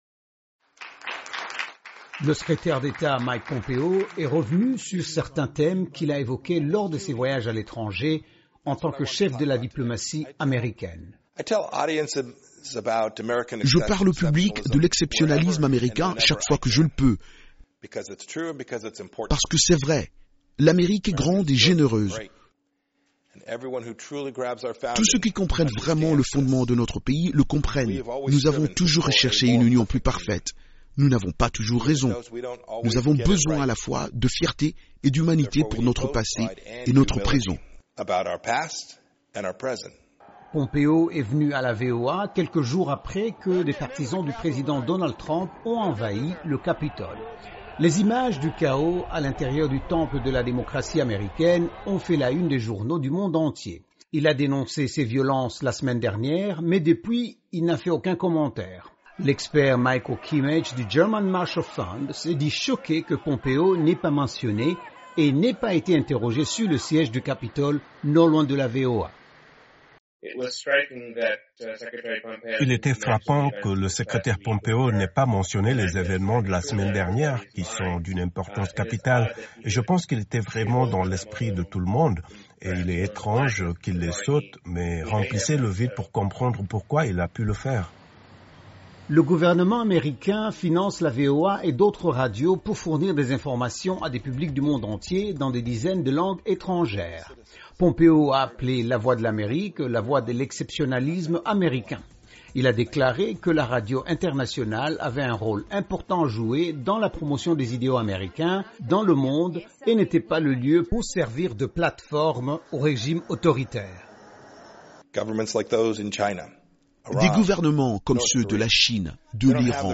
Le secrétaire d'État Mike Pompeo a effectué hier lundi sa premiere visite au siège de la Voix de l’Amerique a Washington. Il a parlé de l'importance de la radio et de l'histoire de l'exceptionnalisme américain dans le monde.